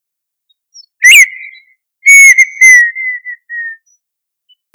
El canto comienza siendo fuerte y “monosilábico”, luego es más melancólico y de dos o tres “sílabas”.